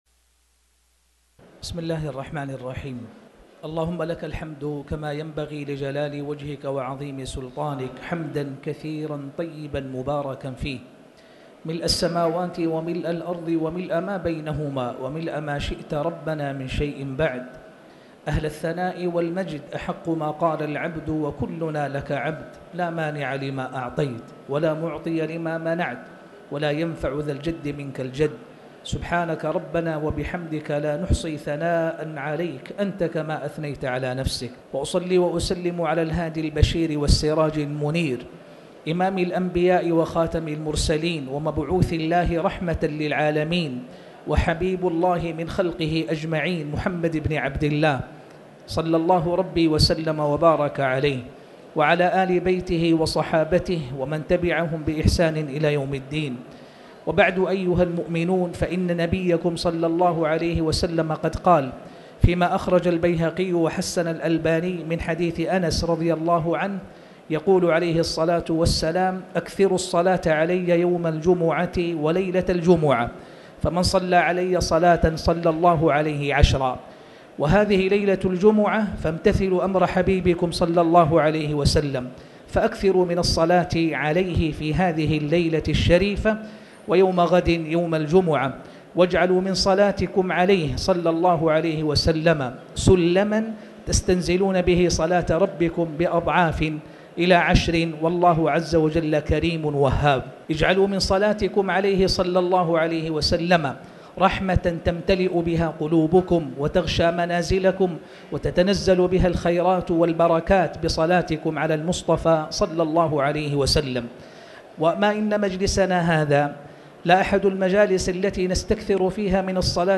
تاريخ النشر ٦ جمادى الآخرة ١٤٣٩ هـ المكان: المسجد الحرام الشيخ